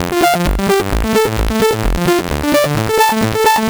Warning Station F 130.wav